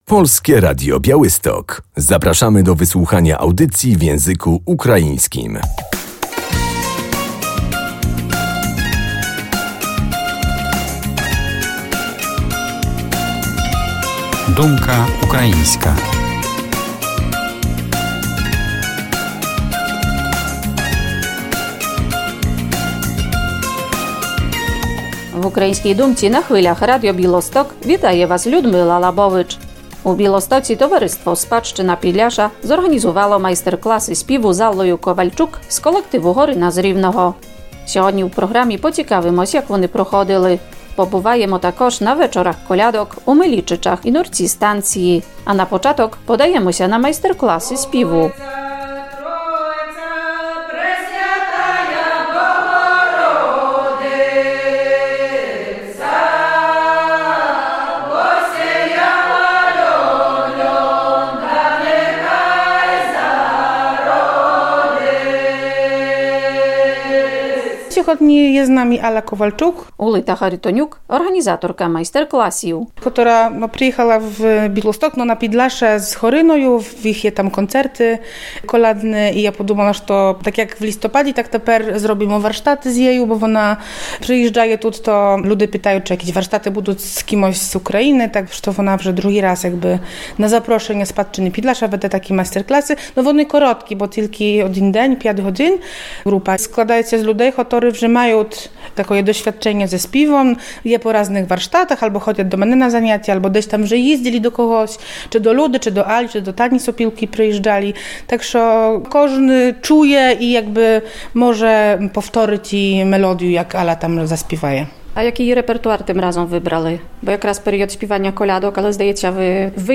Koncerty kolędnicze w Milejczycach i Nurcu-Stacji 24.01.2024